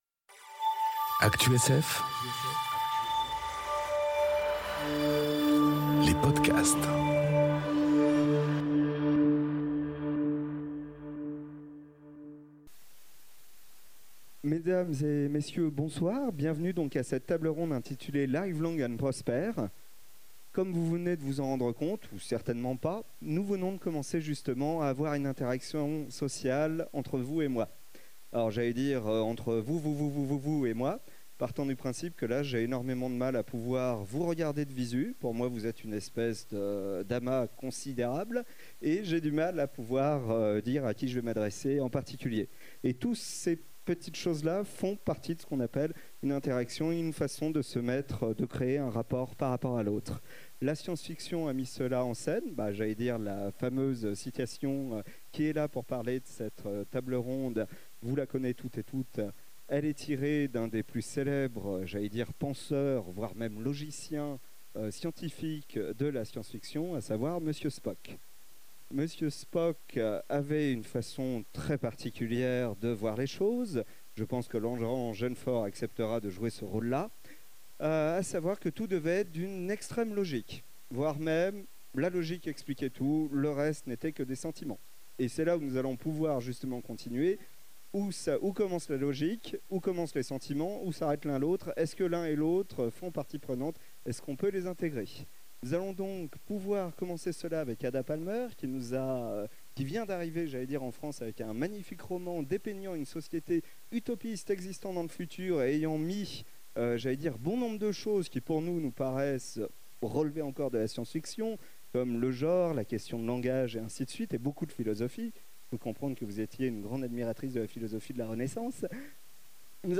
Utopiales 2019 - Live long and prosper